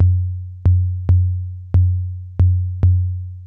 SINE BASS -R.wav